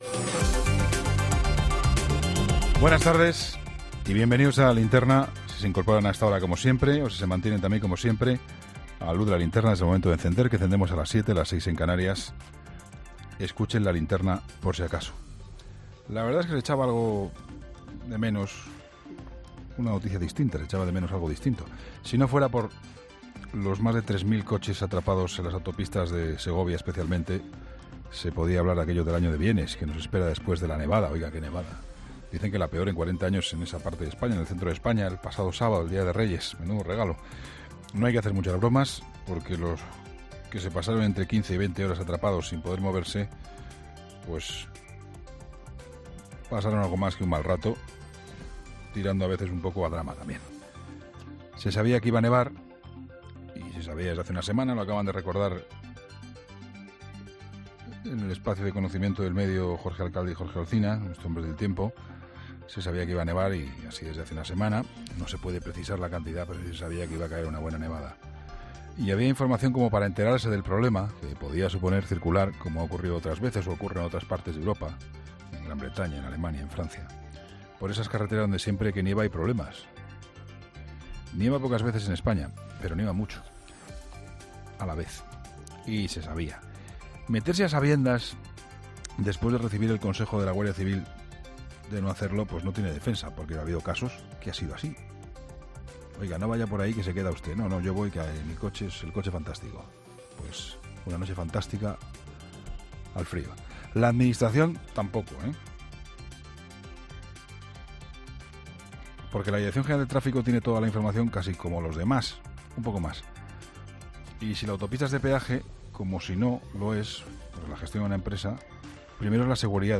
En análisis de actualidad de Juan Pablo Colmenarejo de este lunes 8 de enero a las 20h